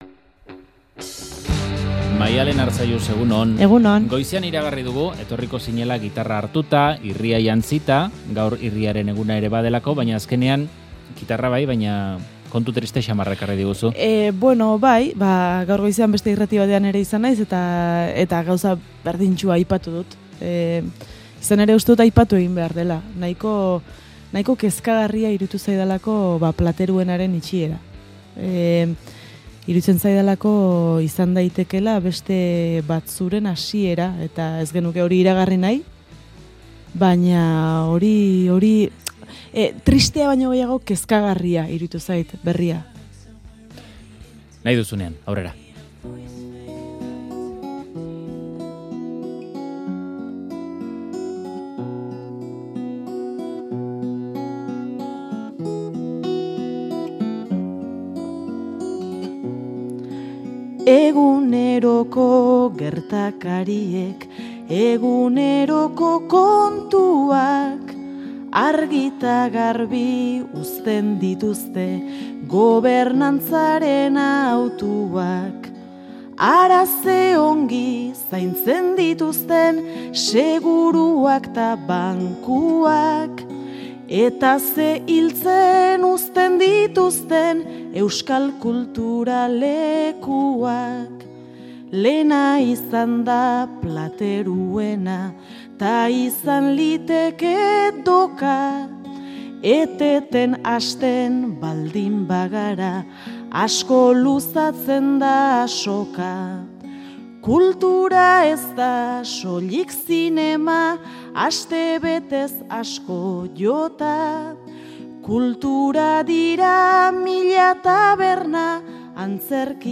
Euskal kulturak bizi duen egoera gogorraz aritu da kantuan